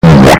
Diarrhea Noise: Instant Play Sound Effect Button